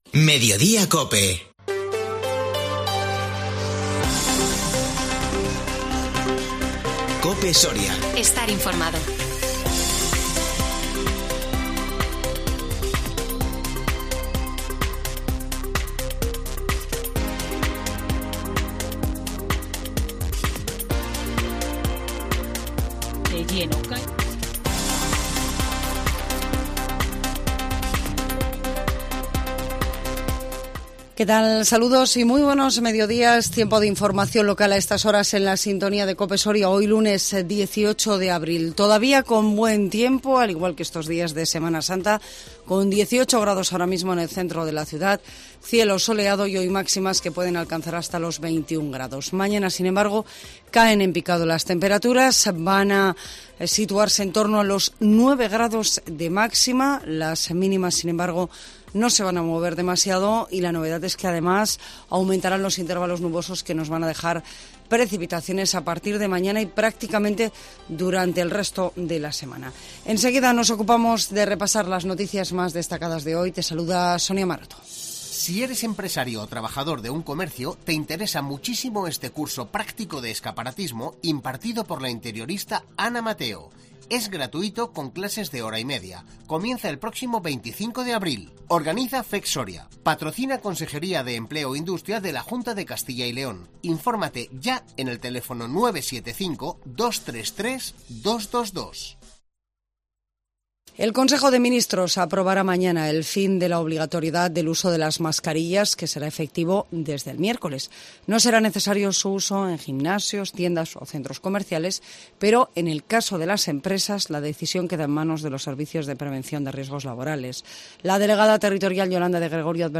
INFORMATIVO MEDIODÍA COPE SORIA 18 ABRIL 2022